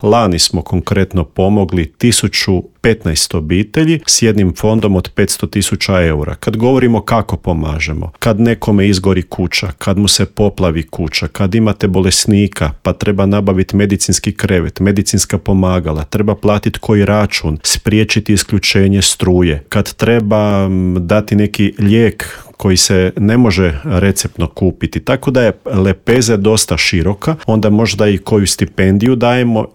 O projektu smo u Intervjuu MS-a razgovarali